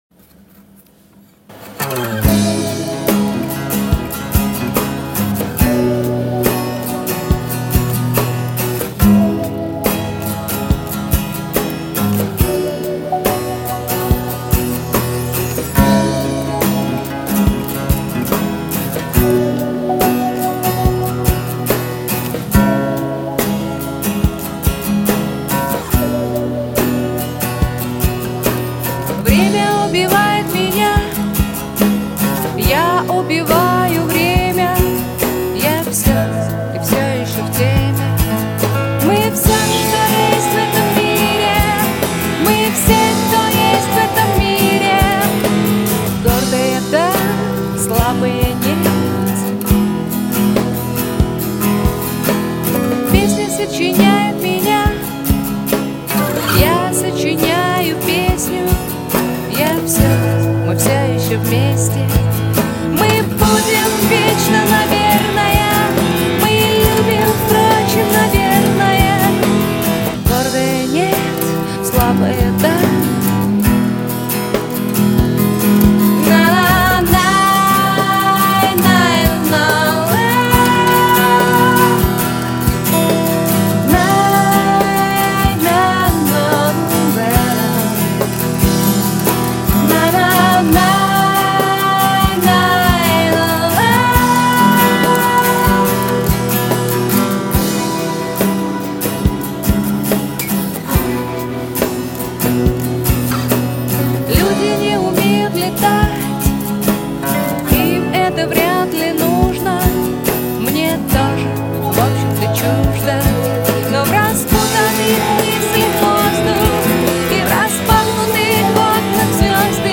Sound: Stereo